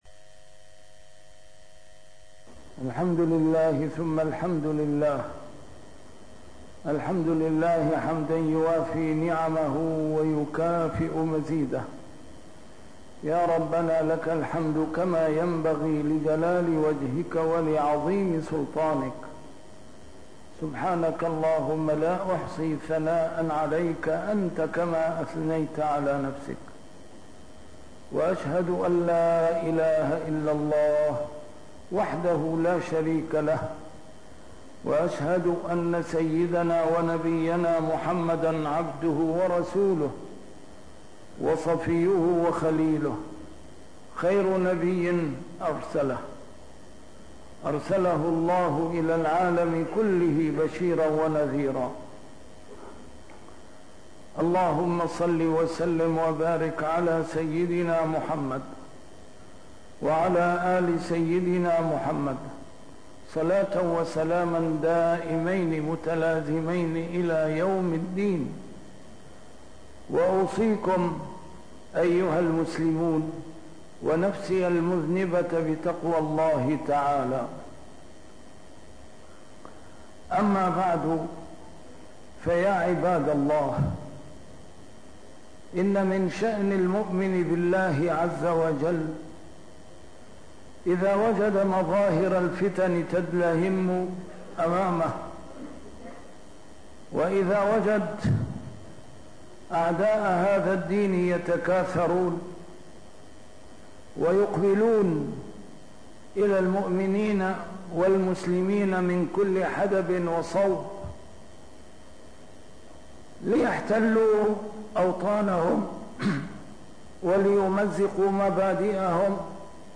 A MARTYR SCHOLAR: IMAM MUHAMMAD SAEED RAMADAN AL-BOUTI - الخطب - أين (فإِيَّايَ فَارْهَبُونِ) في حياة المسلمين؟